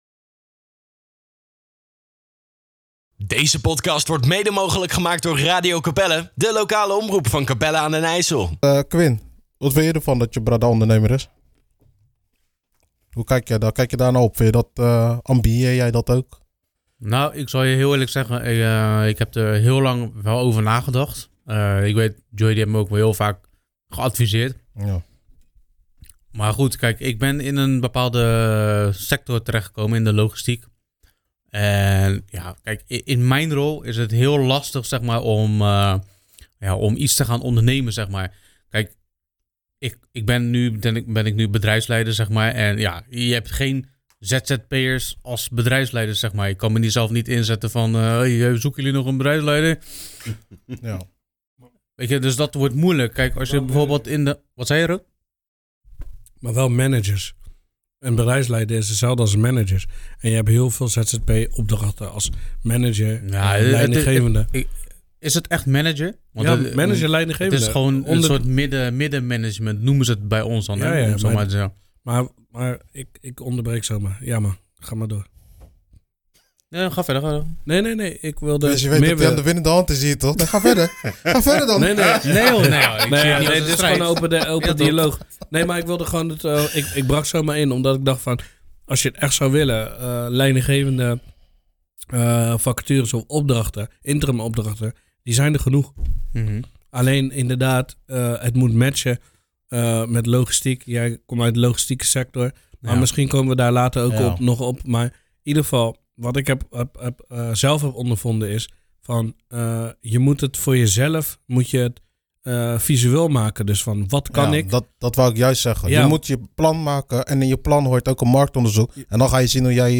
Ze praten openhartig in dit tweeluik over hun reis als ondernemer en krijgen pittige vragen.